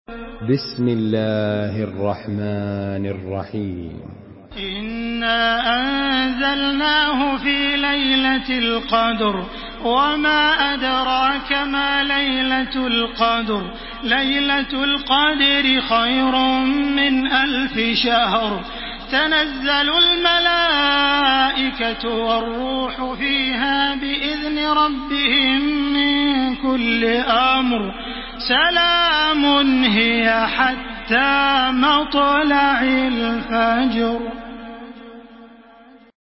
تحميل سورة القدر بصوت تراويح الحرم المكي 1433
مرتل حفص عن عاصم